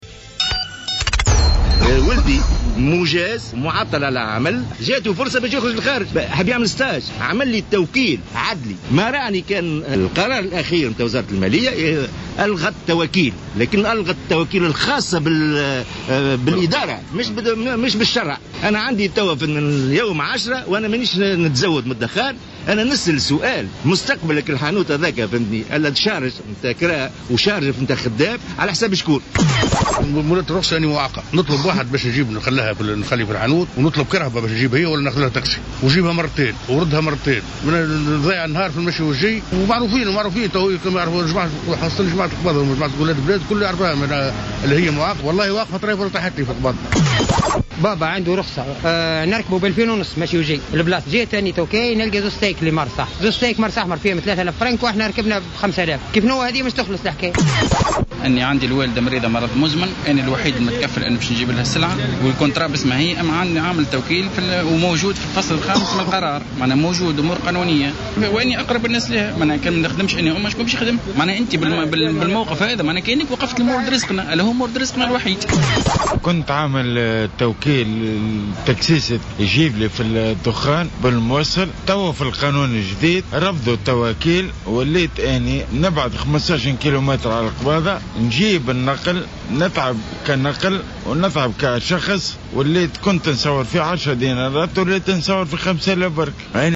روبورتاج